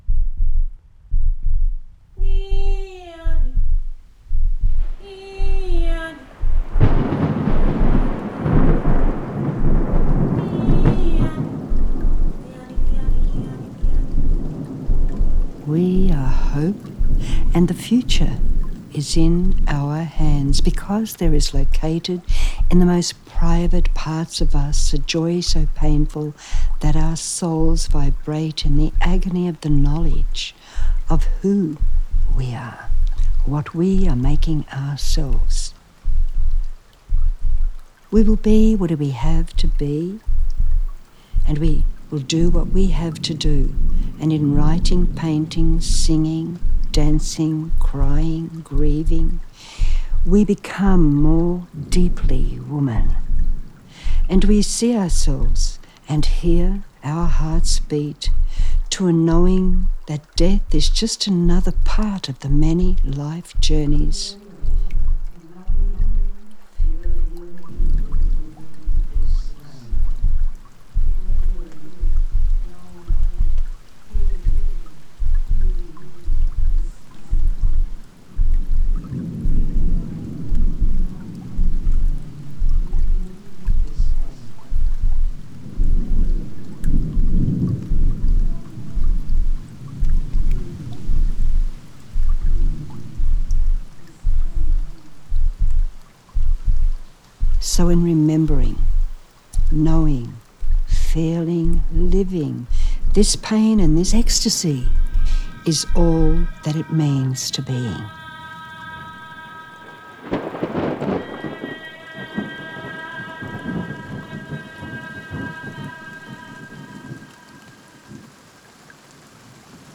The work advocates and induces deep listening with which to understand the anger and underlying grieving born of serial trauma suffered by generations of Australia’s Indigenous peoples. As a soft crackling suggests a gentle fire at listen_UP’s centre, a very present, lone female voice, pondering inherited and personally experienced suffering, is textured with heartbeat, the rumble of restless weather and a singer expressively uttering a mutating syllable sequence emotionally in tandem with the speaker’s narrative in a sound world of gently shifting perspectives.